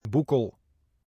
phát âm) là một đô thị và thị xã ở tỉnh Noord-Brabant phía nam Hà Lan.